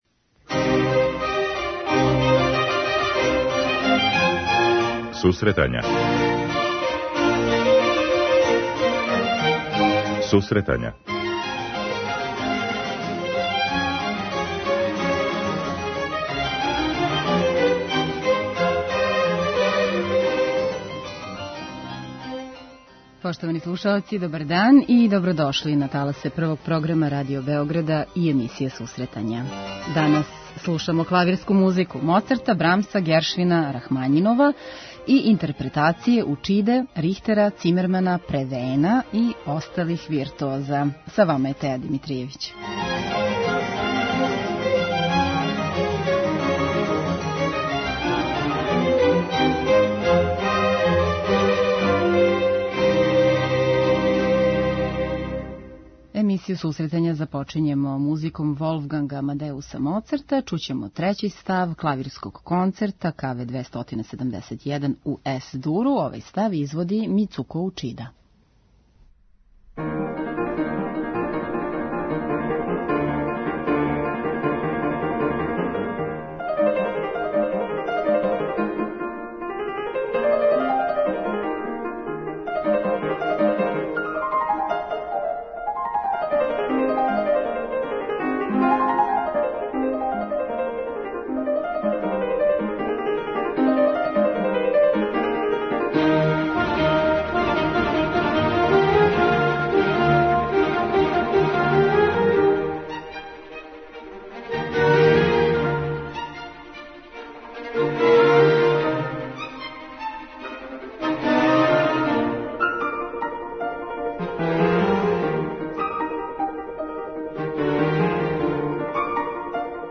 интерпретације чувених пијаниста